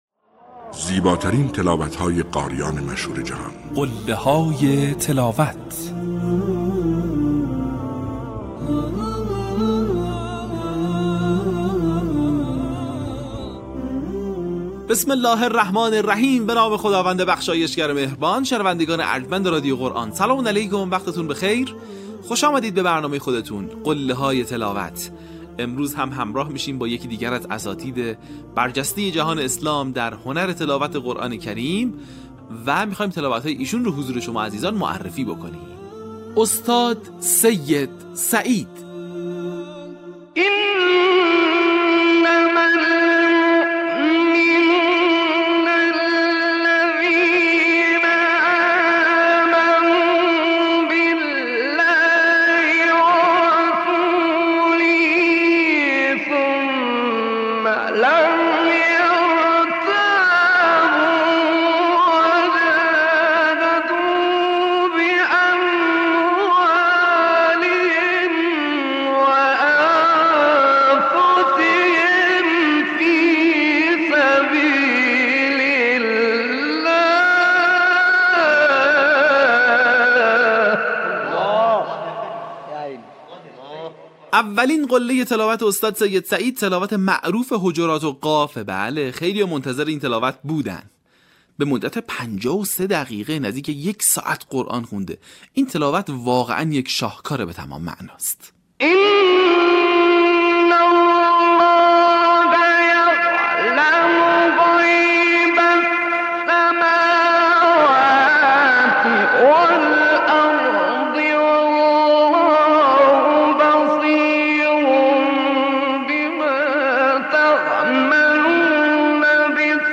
این مجموعه شامل تلاوت‌های ماندگار قاریان بین‌المللی مصری است که تاکنون 40 قسمت آن از ایکنا منتشر شده است. در نخستین قسمت، مجموعه‌ای از تلاوت‌های شنیدنی اساتید محمد عبدالعزیز حصان، سید سعید، عبدالوهاب طنطاوی، عبدالفتاح طاروطی، محمود طبلاوی، عبدالمنعم طوخی، محمود علی البنا و شعبان عبدالعزیز صیاد را می‌شنوید.